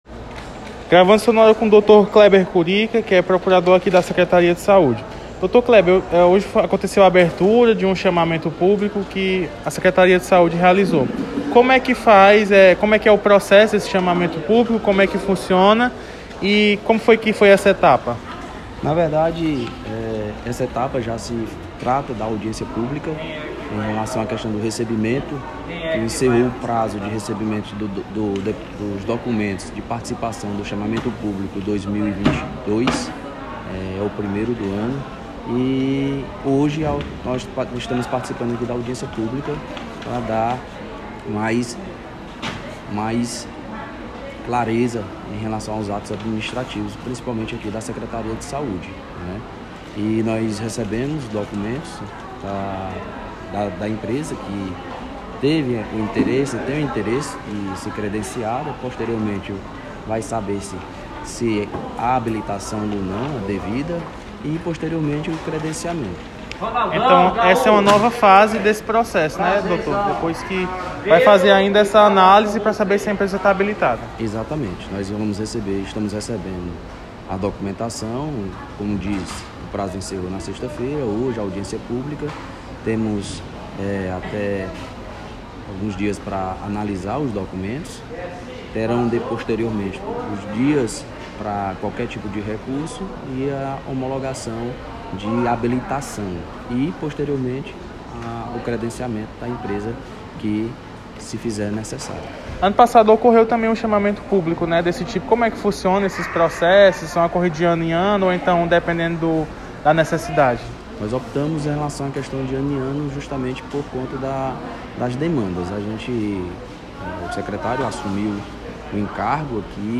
Acompanhe a entrevista na íntegra! https